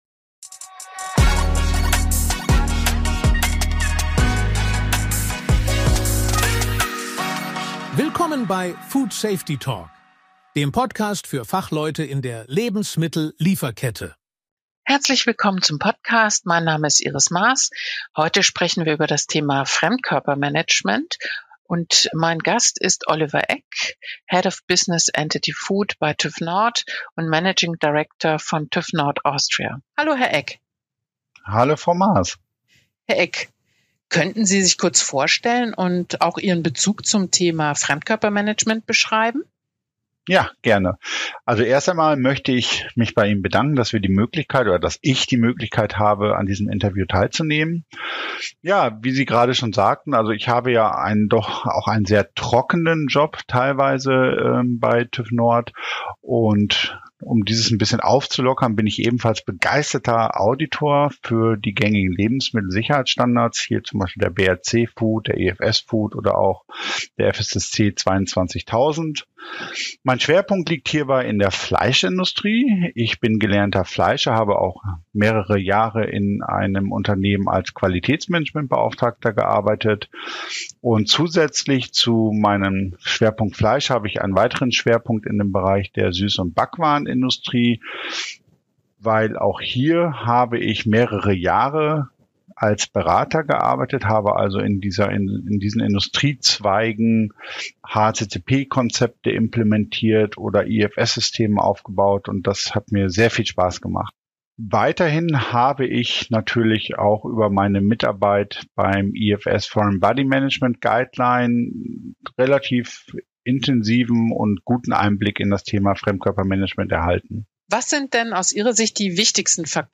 Experteninterview